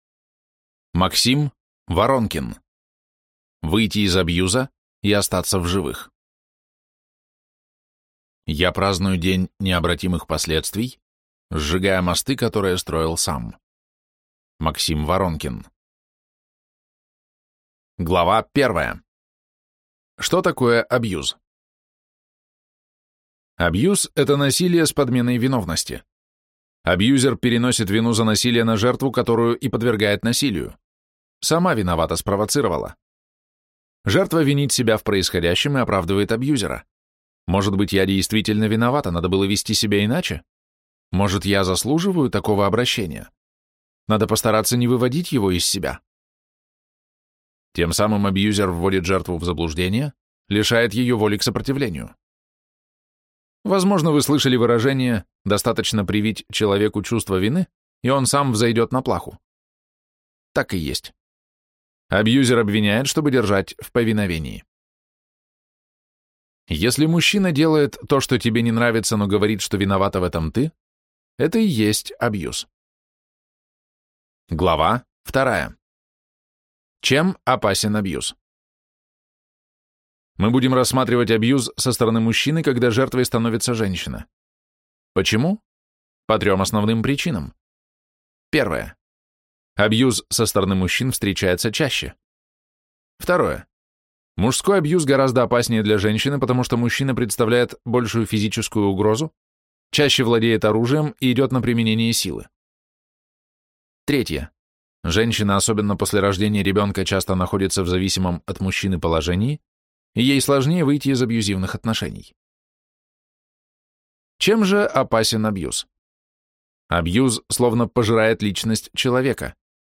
Аудиокнига Выйти из абьюза и остаться в живых | Библиотека аудиокниг